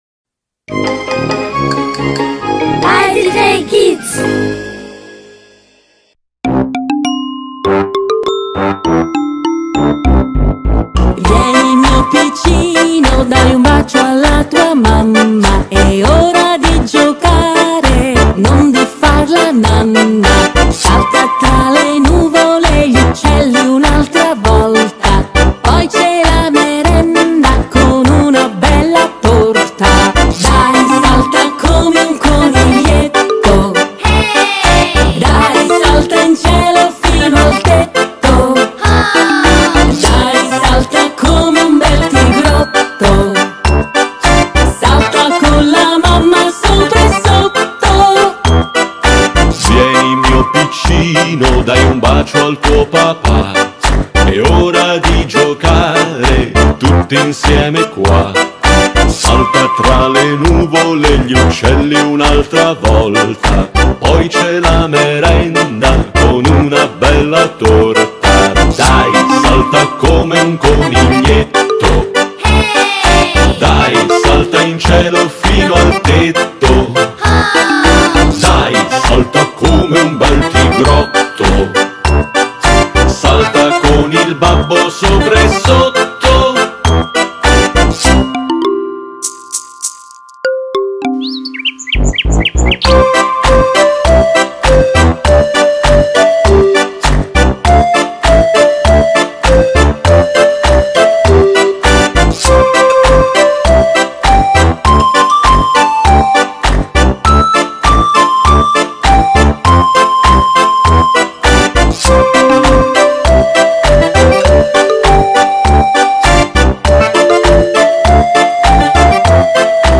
Kein Dialekt
Sprechprobe: Sonstiges (Muttersprache):
Kid song~2.mp3